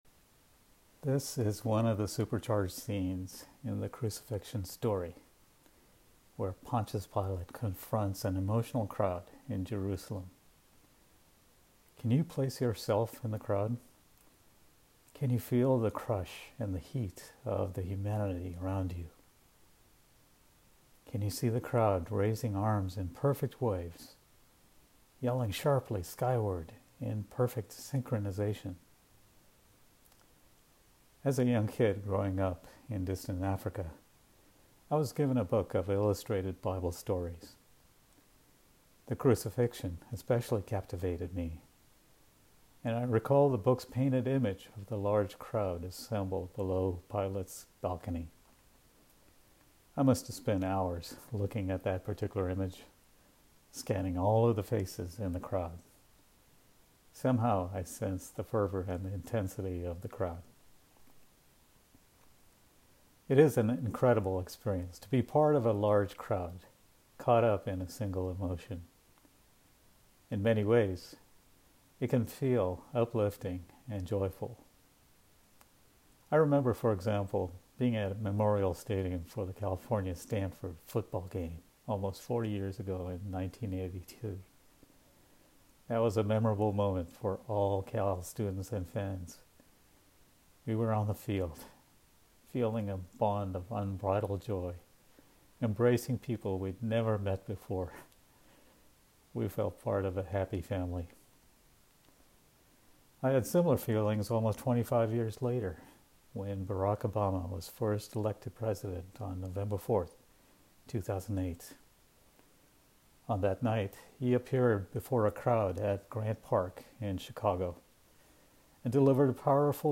Good Friday Reflections